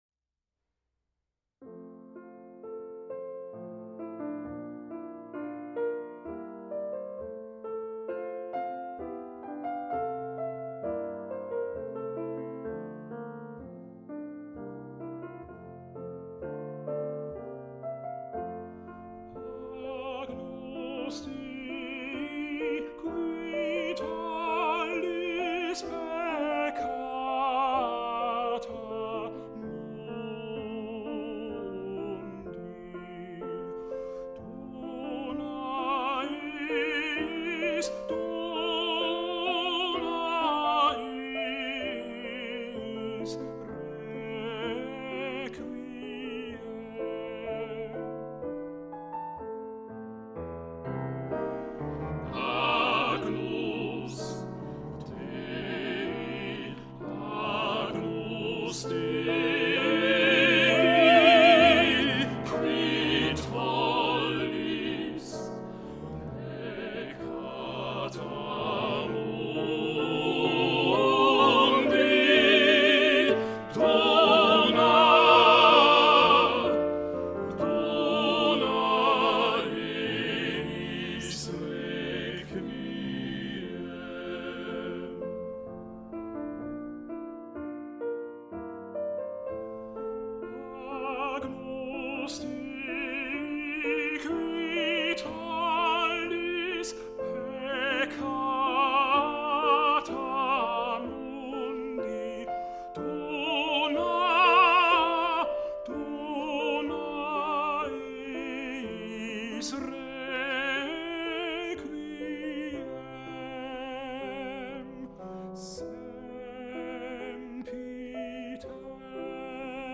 agnus dei tenor 2